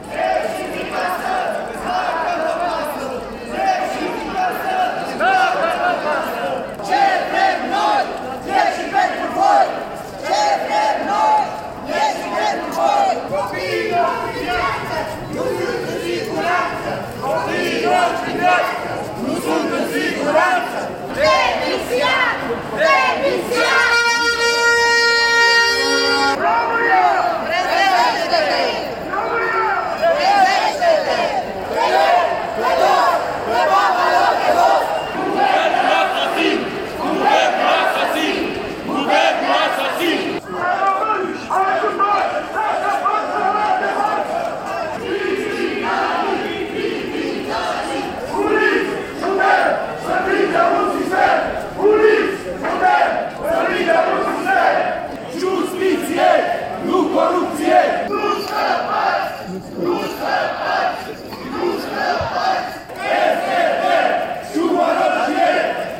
Mai apoi, mulțimea a pornit în marș pe Calea Victoriei, scandând “Ieşiţi din casă dacă vă pasă!”.
Manifestanții au strigat împotriva sistemului corupt și a clasei politice actuale, și au aprins lumânări în fața sediului Ministerului de Interne.
protest-cazul-caracal.mp3